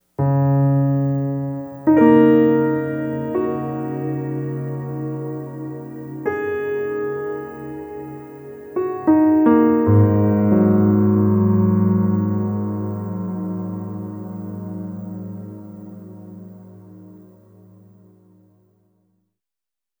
Reverb Piano 01.wav